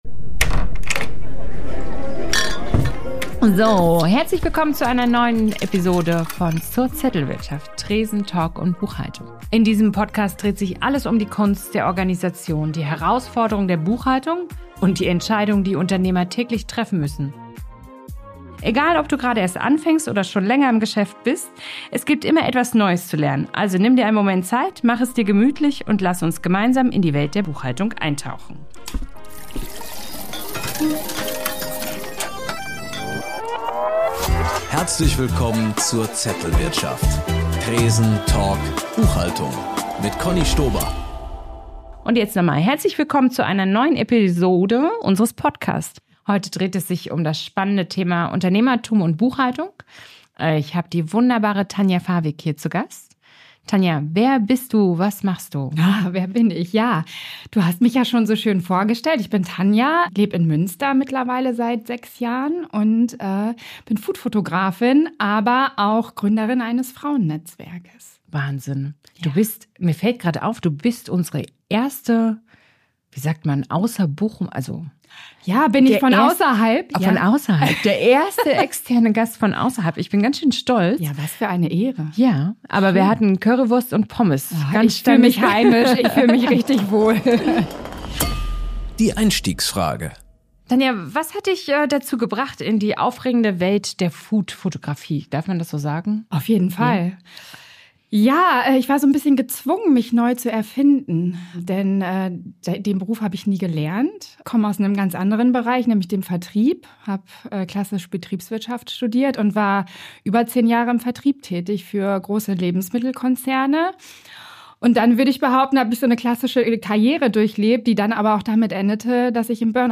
Ein ehrliches, entspanntes Gespräch über Zahlen, Zettel, gutes Essen – und das ganz normale Selbstständigenleben zwischen Tresen, Talk und Zettelwirtschaft.